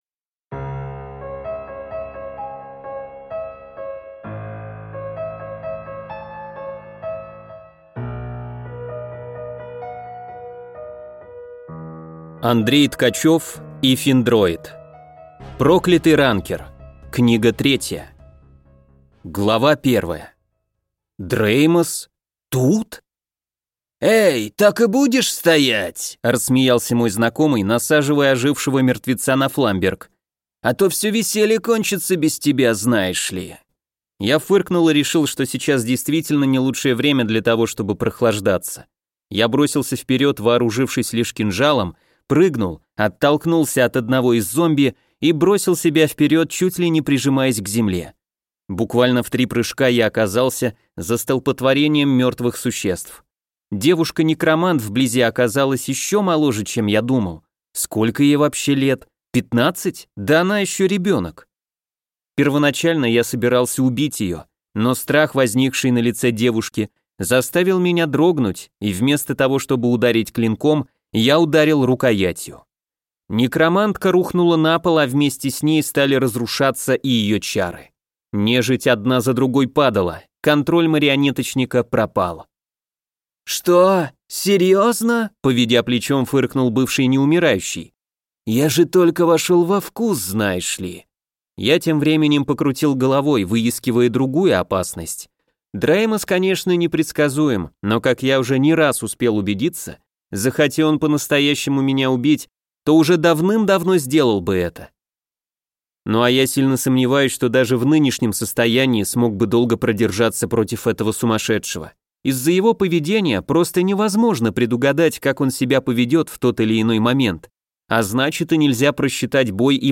Аудиокнига Проклятый ранкер. Книга 3 | Библиотека аудиокниг